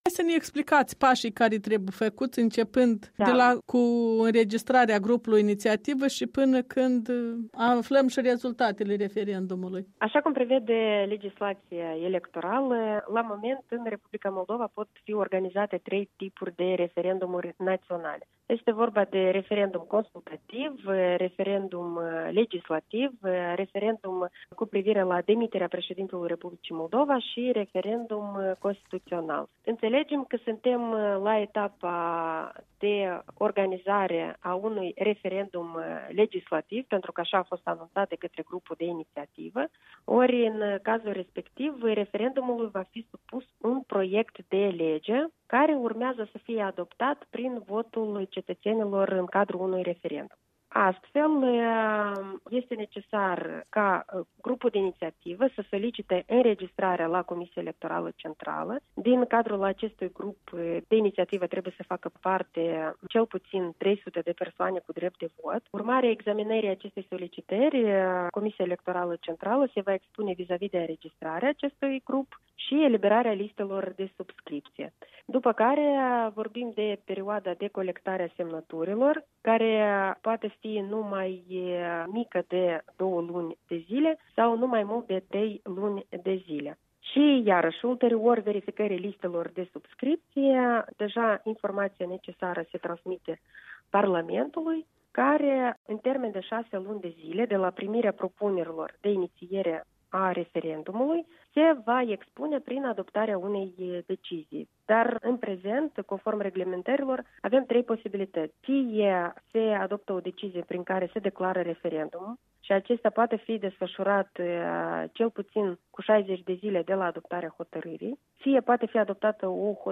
Explicații de la președinta Comisiei Electorale Centrale Alina Russu.